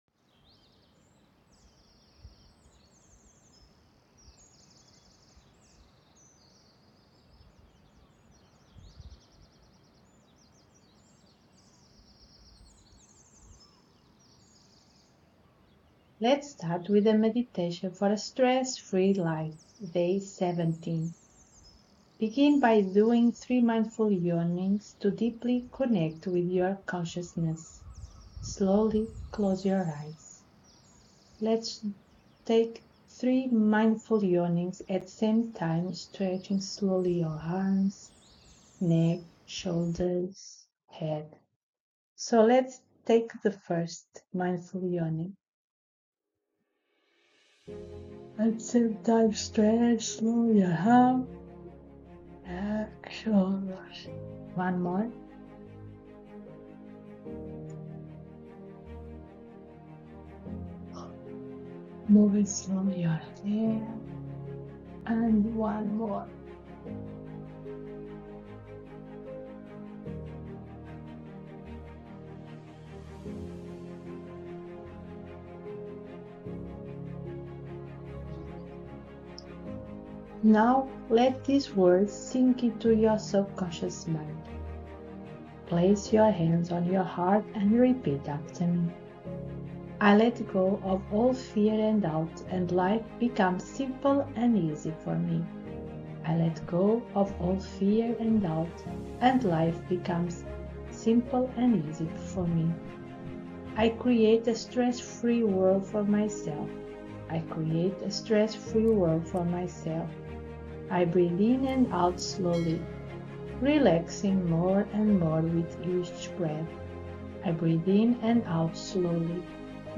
“Stress-Free” Meditation Sample in English (pls use head phones).
VOLTAR-meditation-day-17.mp3